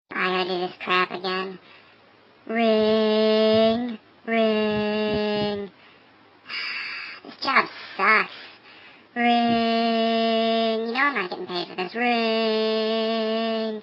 ipod-ring-tones-ringtones.mp3